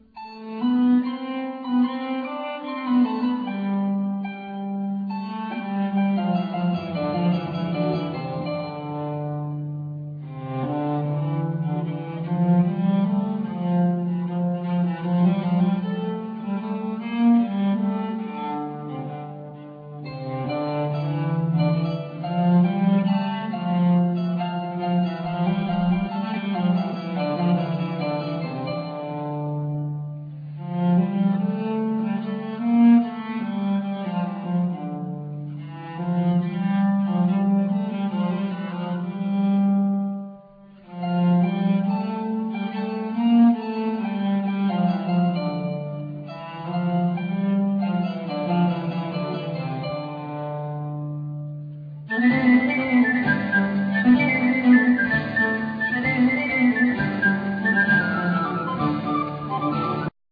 Flute,Recorder,Saxophone,Clarinets,Chalumeaux
Percussions
Soprano
Alto
Tenor
Bass
Viola da Gamba
Cembalo,Regal